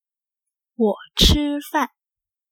ウォ チー ファン